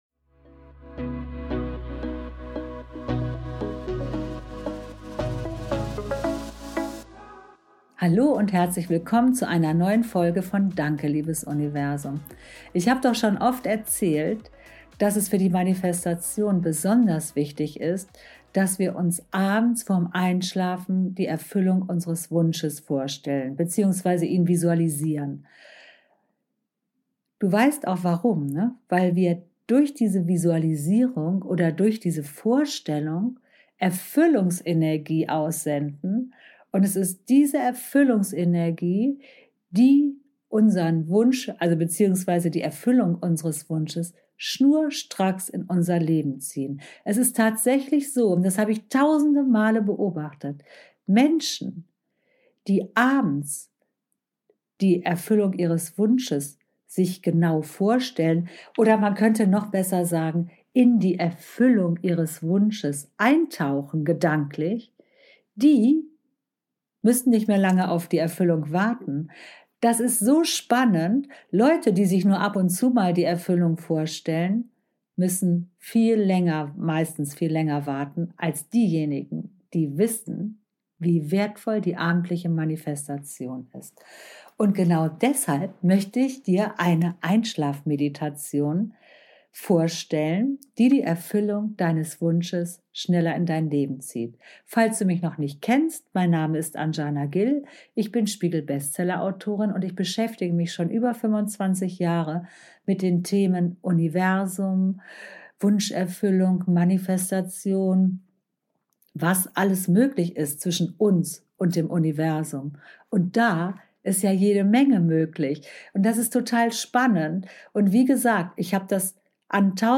In dieser Episode machen wir gemeinsam eine Einschlafmeditation, die deine Manifestation kräftig beschleunigt.